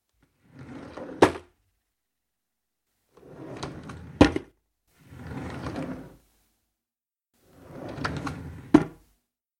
抽屉的打开和关闭
Tag: 打开 关闭 关闭 幻灯片 抽屉